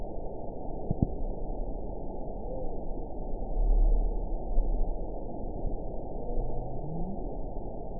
event 921610 date 12/11/24 time 04:18:57 GMT (11 months, 3 weeks ago) score 8.78 location TSS-AB04 detected by nrw target species NRW annotations +NRW Spectrogram: Frequency (kHz) vs. Time (s) audio not available .wav